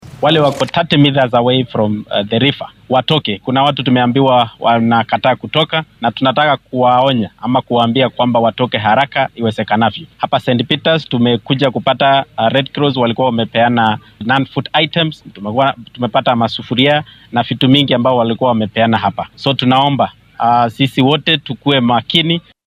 Barasaab ku xigeenka Narok, Tamalinye Koech ayaa arrintan ka hadlay isagoo dhanka kale dadka ilaa 30 miitar u jira webiyada iyo biyo xireennada ka codsaday in ay halkaasi ka guuraan.
Barasaab-ku-xigeenka-Narok.mp3